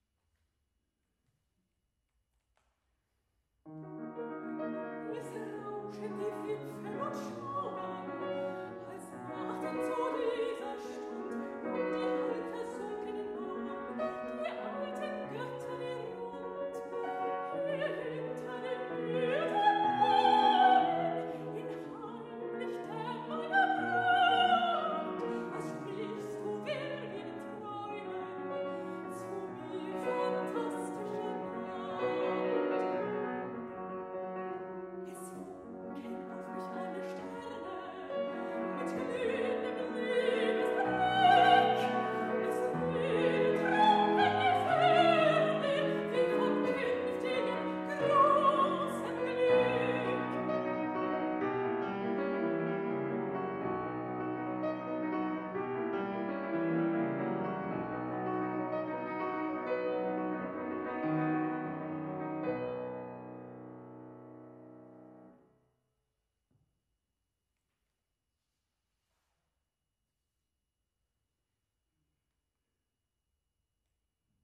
Robert Schumann SOPRAN